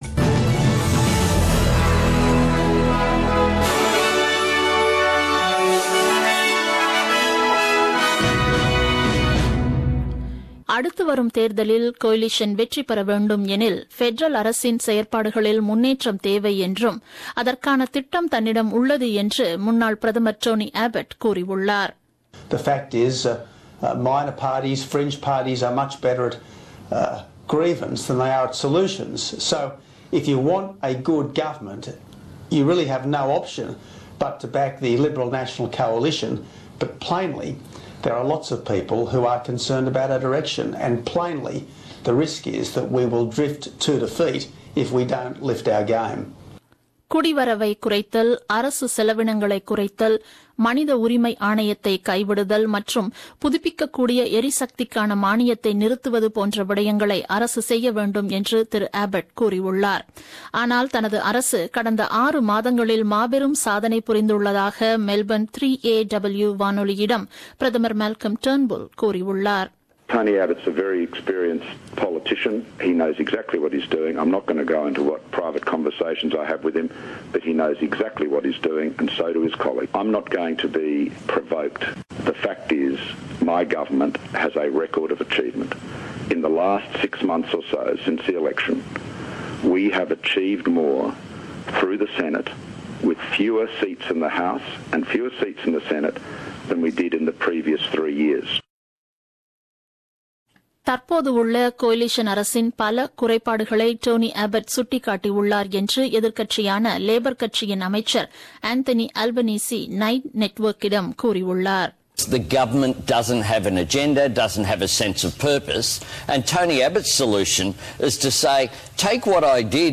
The news bulletin broadcasted on 24 Feb 2017 at 8pm.